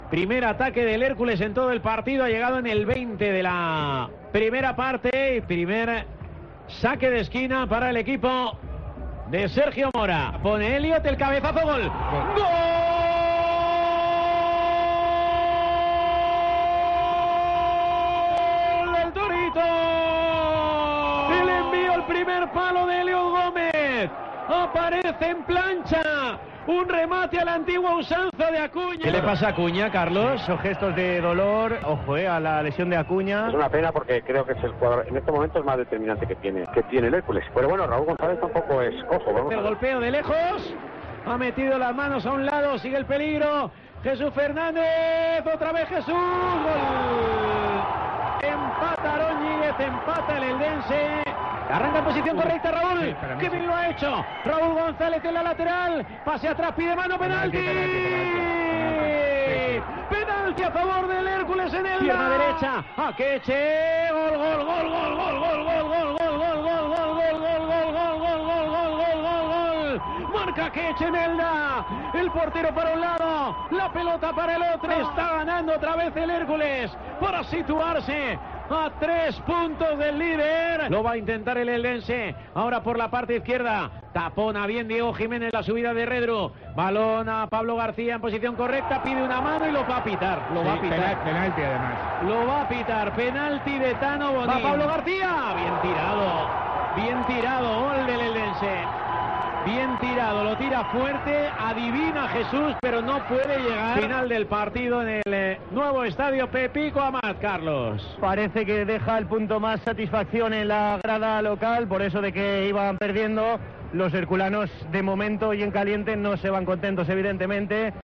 Sonidos del Eldense 2-2 Hércules CF
Así vivimos en Tiempo de Juego Alicante el empate de los blanquiazules en el Nuevo Pepico Amat de Elda